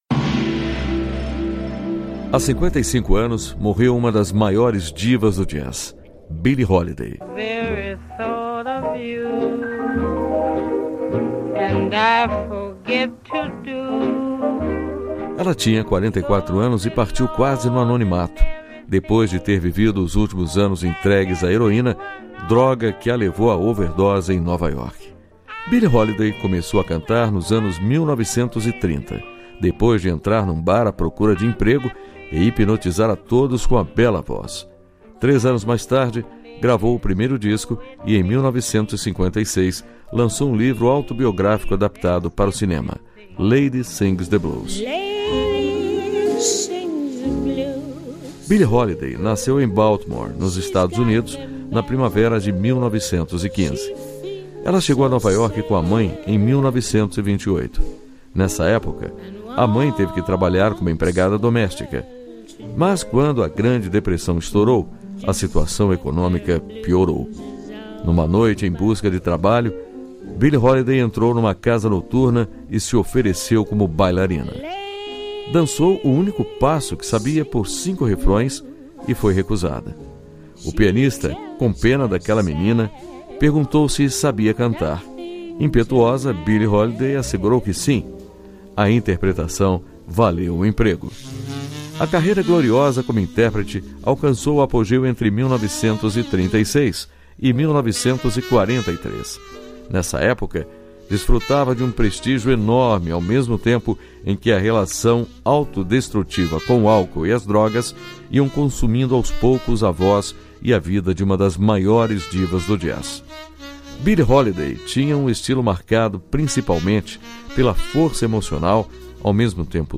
História Hoje : Programete sobre fatos históricos relacionados às datas do calendário.